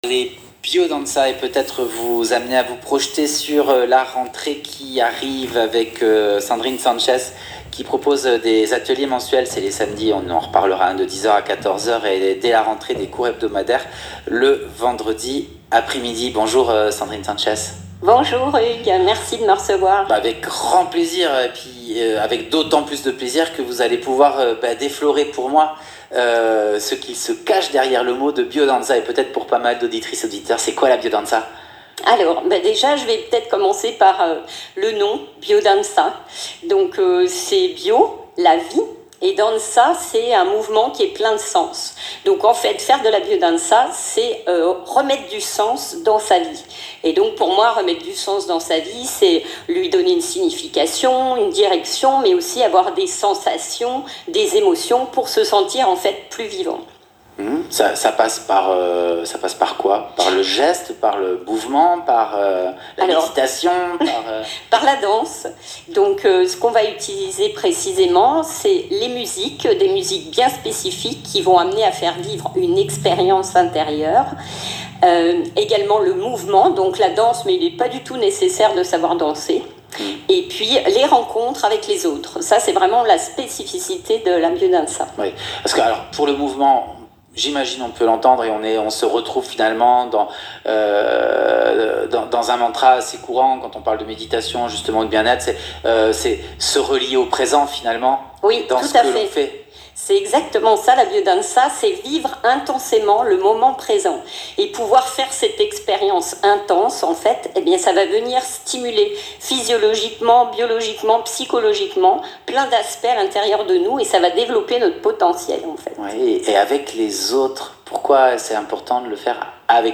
Voici une interview d’une dizaine de minutes que j’ai donné sur Radio Transparence le 11 juillet 25 et qui explique ce qu’est la BIODANZA, bonne écoute !!